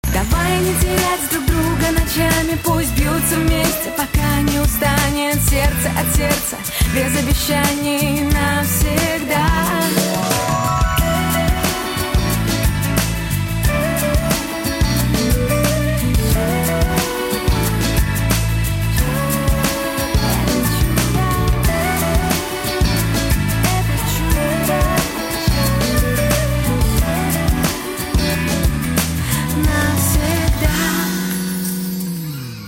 • Качество: 128, Stereo
поп
женский вокал
спокойные